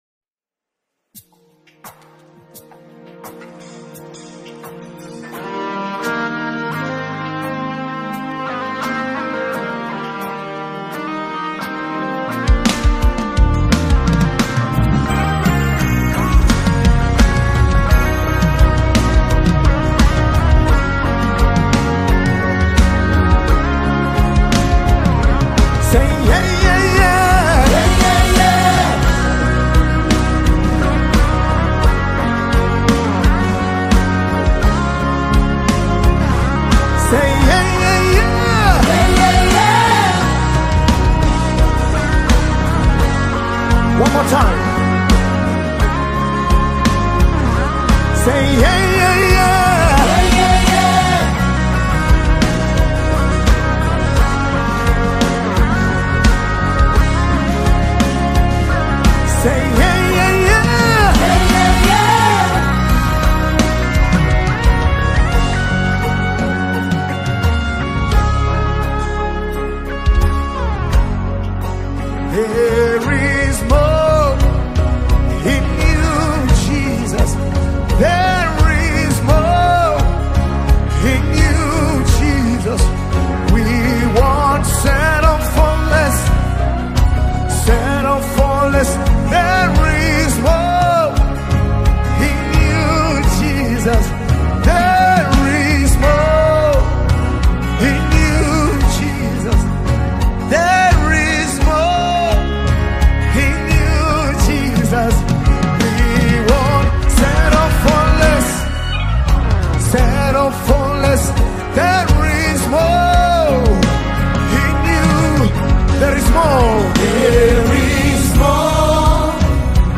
Tanzanian Gospel group
Gospel song